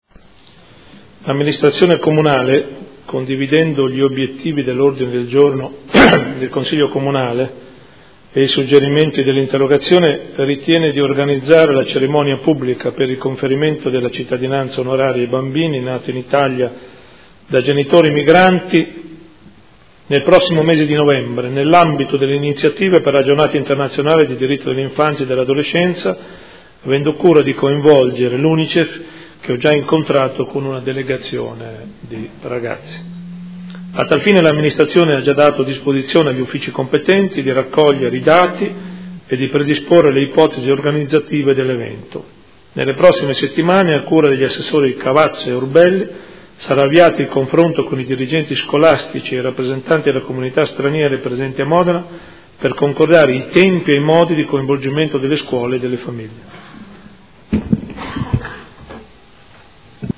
Sindaco